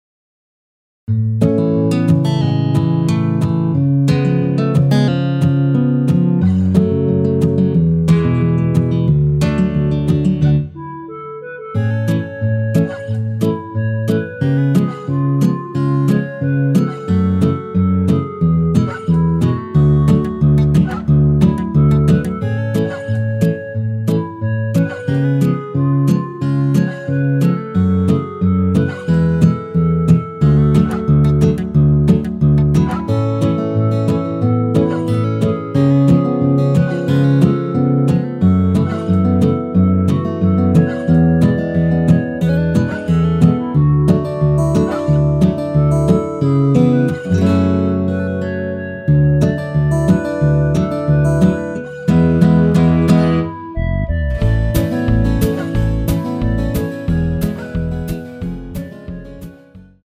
원키에서(-2)내린 멜로디 포함된 MR입니다.
멜로디 MR이란
앞부분30초, 뒷부분30초씩 편집해서 올려 드리고 있습니다.
중간에 음이 끈어지고 다시 나오는 이유는